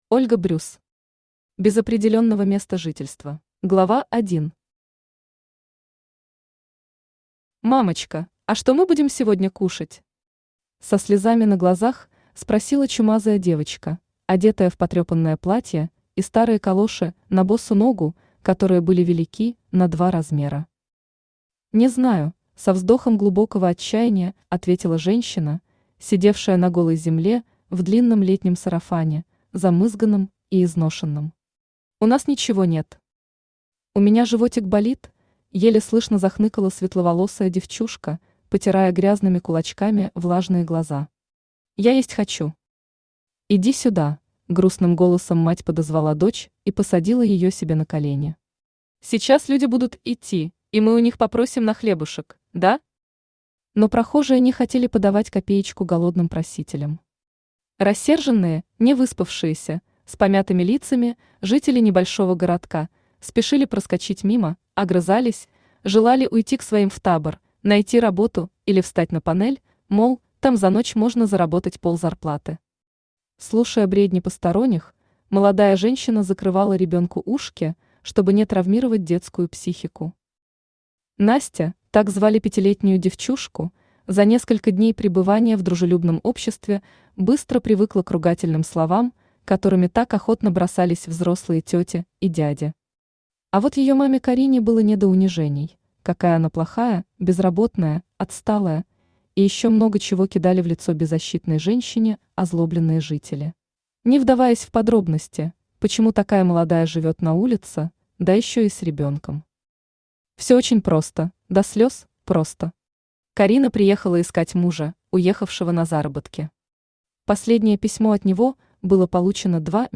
Аудиокнига Без определённого места жительства | Библиотека аудиокниг
Aудиокнига Без определённого места жительства Автор Ольга Брюс Читает аудиокнигу Авточтец ЛитРес.